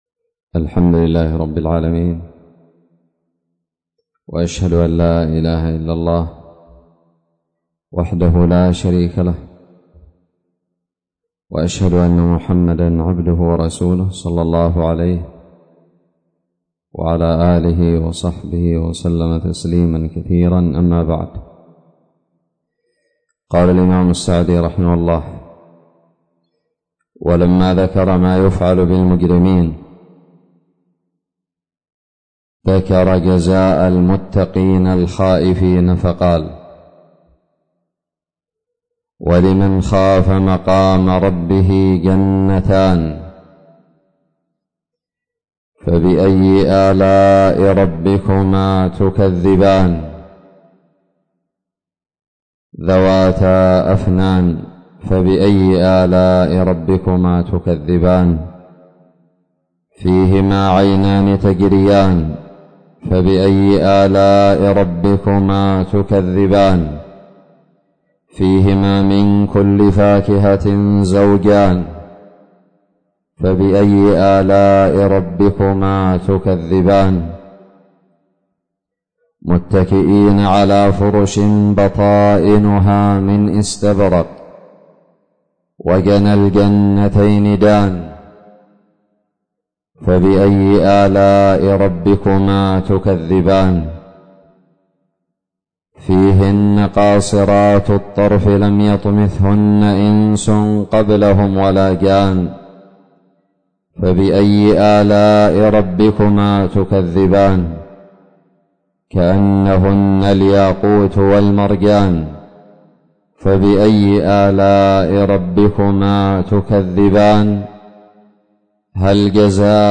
الدرس الخامس من تفسير سورة الرحمن
ألقيت بدار الحديث السلفية للعلوم الشرعية بالضالع